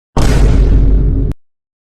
Sounds of "boom" download and listen online
• Category: Boom